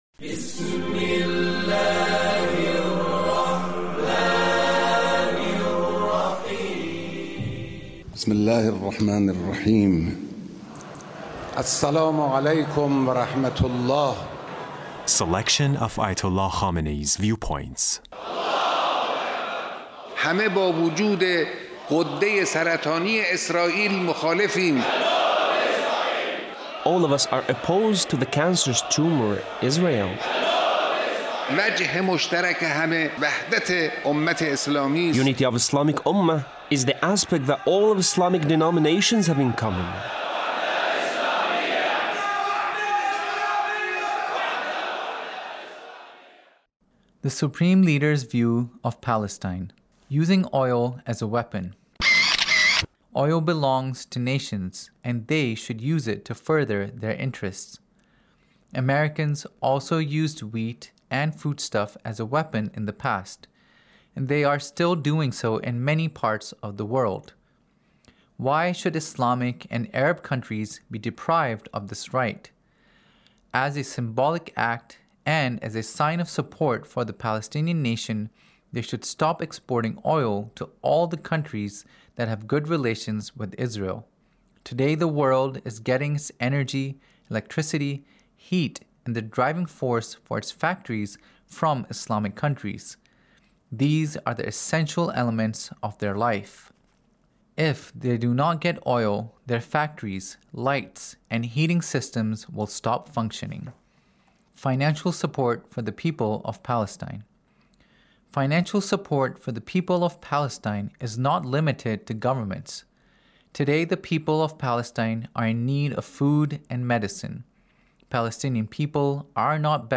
Leader's Speech on Palestine